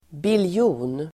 Ladda ner uttalet
Uttal: [bilj'o:n]